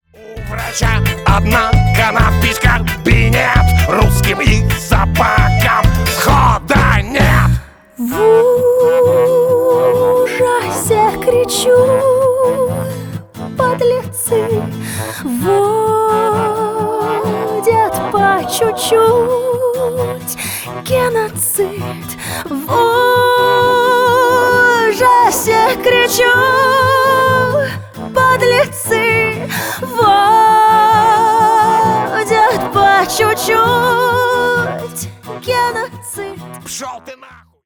Рок Металл
весёлые